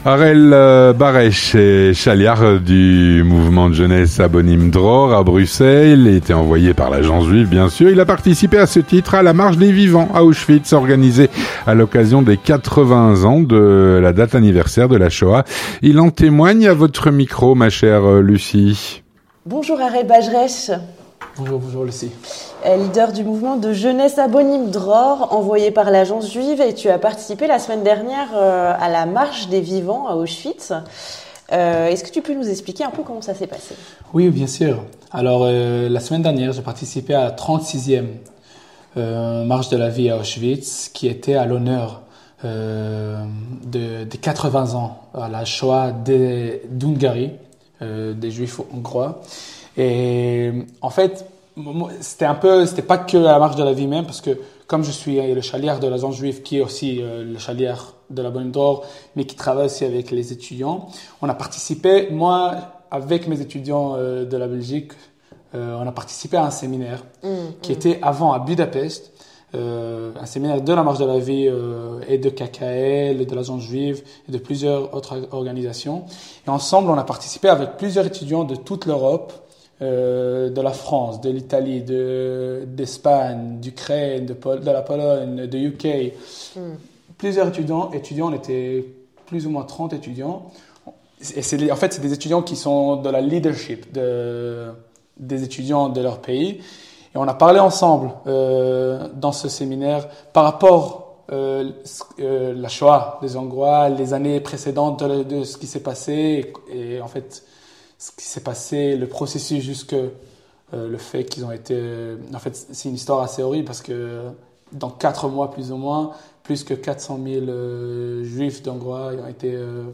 Vue de Belgique - Témoignage sur la "marche des vivants", à Auschwitz, organisée à l’occasion des 80 ans de la Shoah.